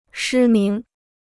失明 (shī míng) Free Chinese Dictionary